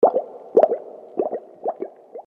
• Качество: 320, Stereo
Вода